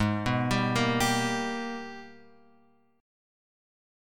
G# Minor Major 9th